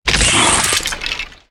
语音
LOE_019_UnearthedRaptor_Death.ogg